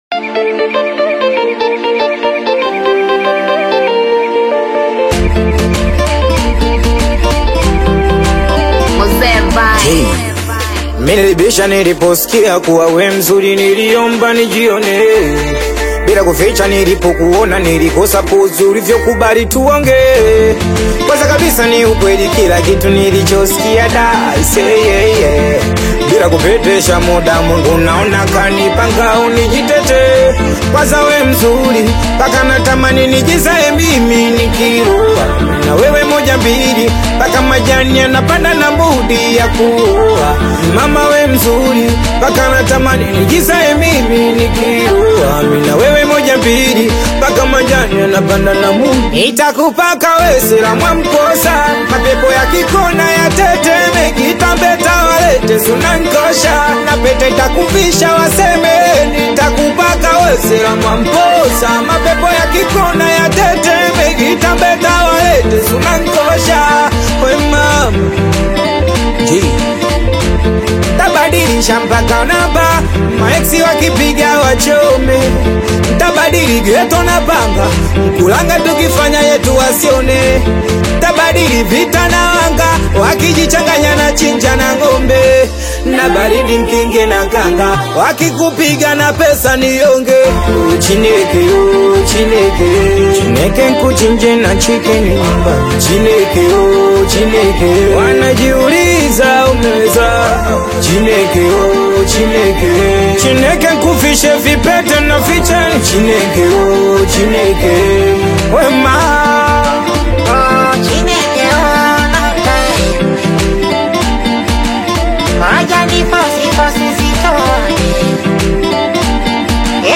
energetic Tanzanian Singeli/Bongo Flava single
Driven by rapid Singeli beats and expressive Swahili lyrics
lively delivery and authentic urban sound